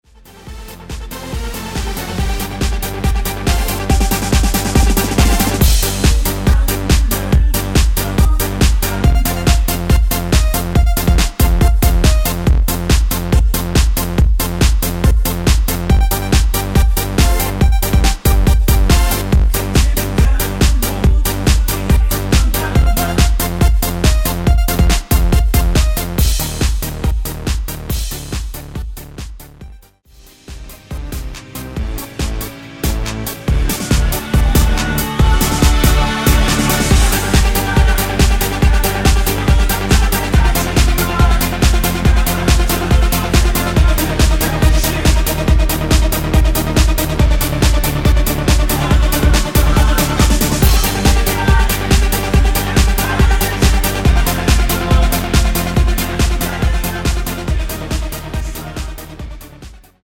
원키(-3)내린 코러스 포함된 MR 입니다.(미리듣기 참조)
Gm
앞부분30초, 뒷부분30초씩 편집해서 올려 드리고 있습니다.
중간에 음이 끈어지고 다시 나오는 이유는